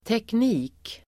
Uttal: [tekn'i:k]